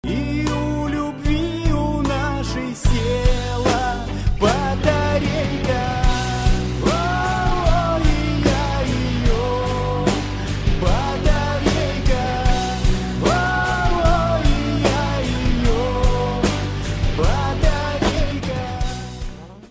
• Качество: 128, Stereo
грустные
Cover
баллада